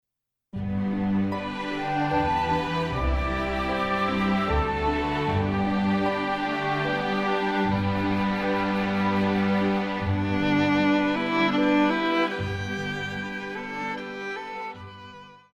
小提琴
管弦樂團
童謠,經典曲目,傳統歌曲／民謠,古典音樂
演奏曲
獨奏與伴奏
有主奏
有節拍器
曲調委婉抒情，節奏輕快流暢，略帶憂傷情緒，是一首朝鮮族具有代表性的民歌。